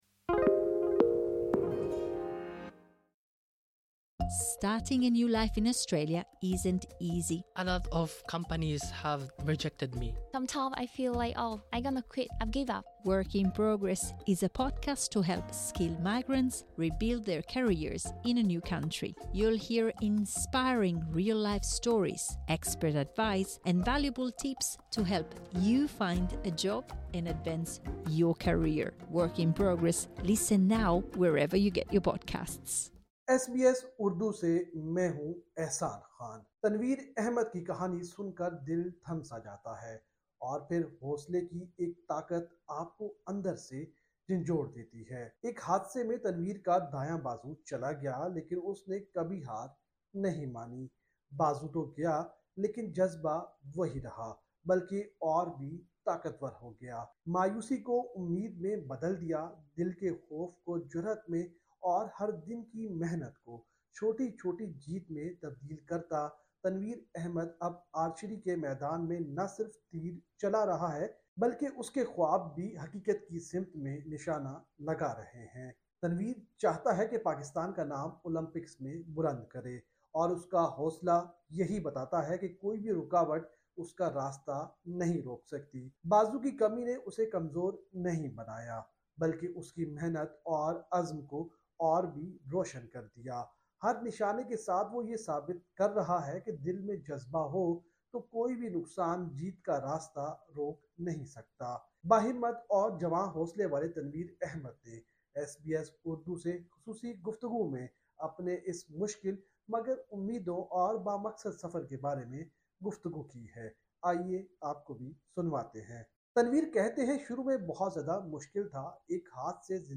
رپورٹ